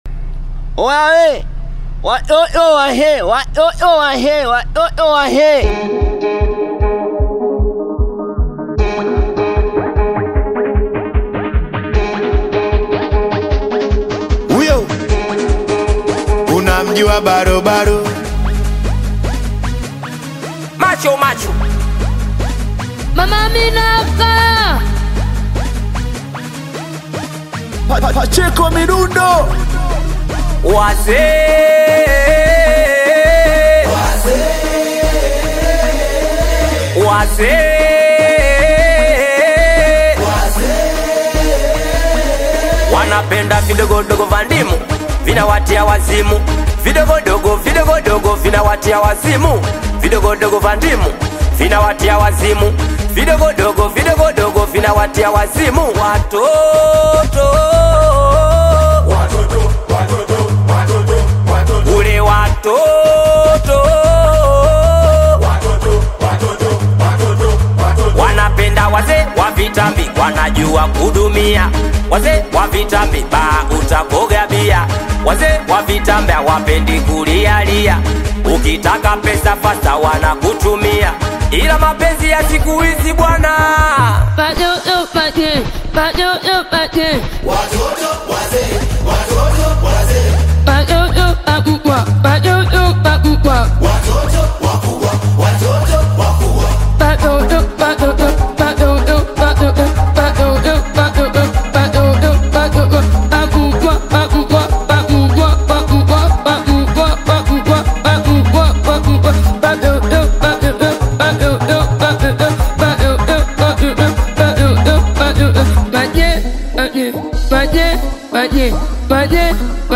energetic collaboration